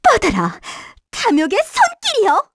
Erze-Vox_Skill4_kr.wav